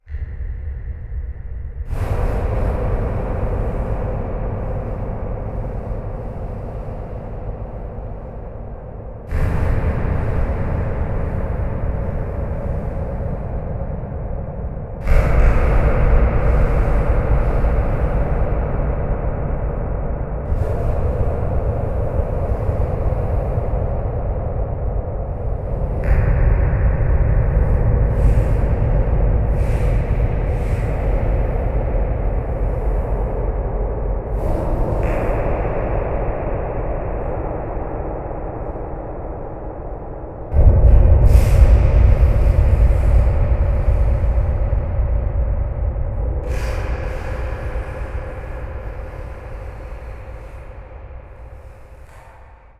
Muffled Distant Explosion
bang battle blast blow-up bomb boom demolition destroy sound effect free sound royalty free Memes